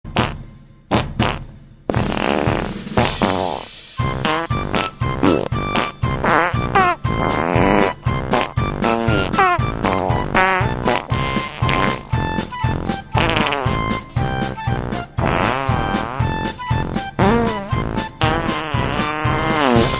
Kategori Sjove